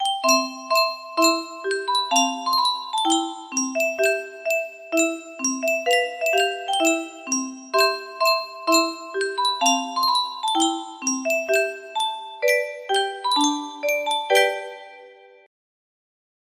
Yunsheng Music Box - The Pirates of Penzance 1974 music box melody
Full range 60